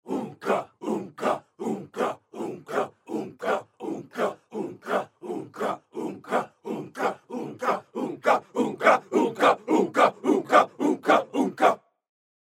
Cultural